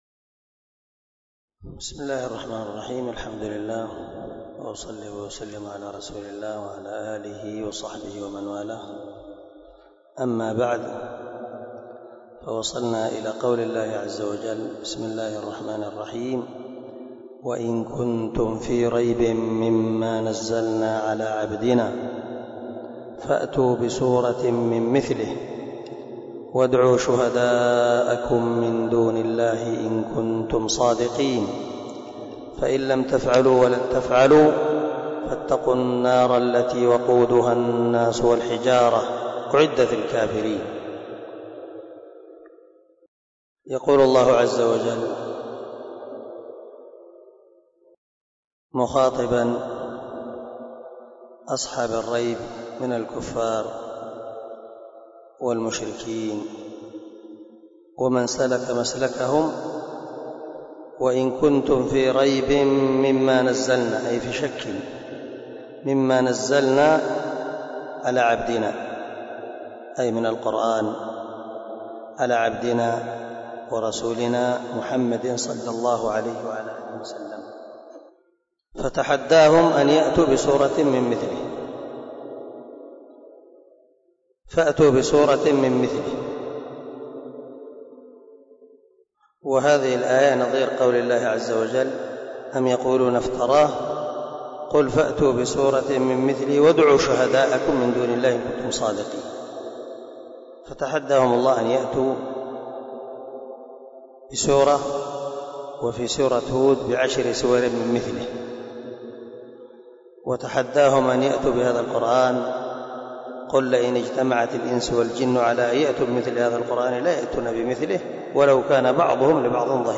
019الدرس 9 تفسير آية ( 23 - 24 ) من سورة البقرة من تفسير القران الكريم مع قراءة لتفسير السعدي
دار الحديث- المَحاوِلة- الصبي